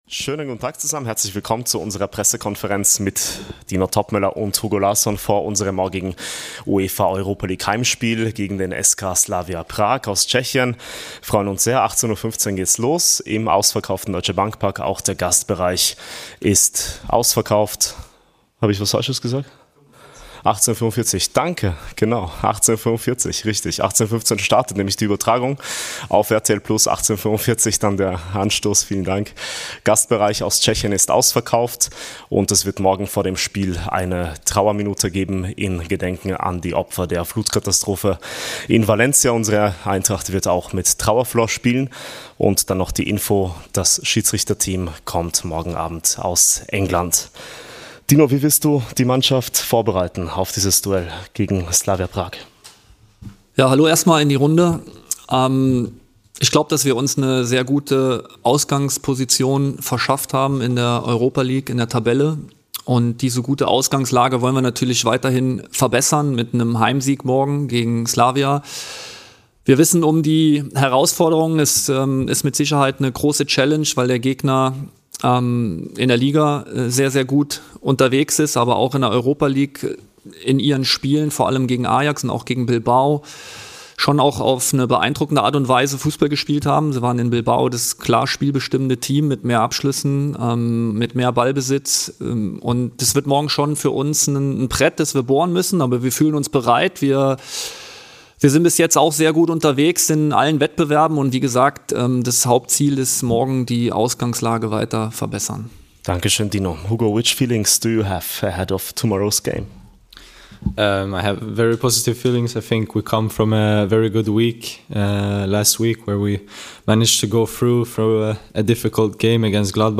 Die Pressekonferenz mit Cheftrainer Dino Toppmöller und Hugo Larsson vor unserem nächsten Europa-League-Heimspiel gegen den Tabellenführer aus Tschechien - live.